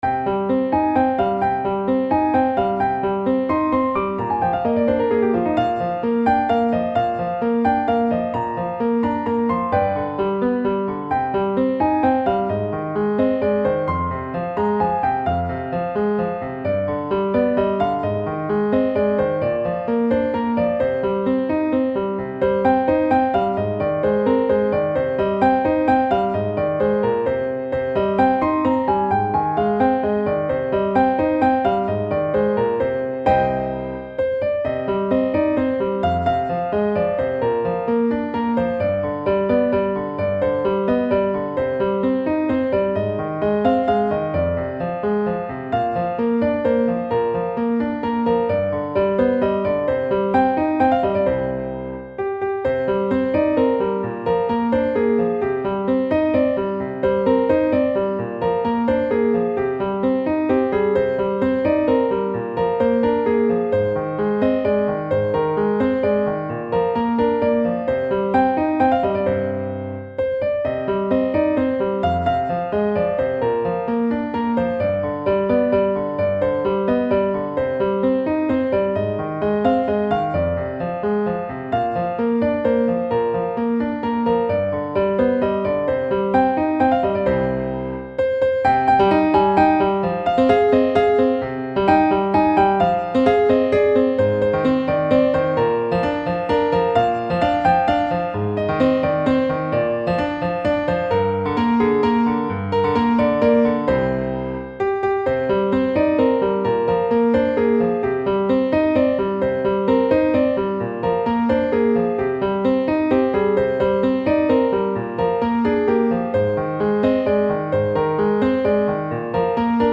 نت پیانو